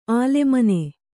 ♪ ālemane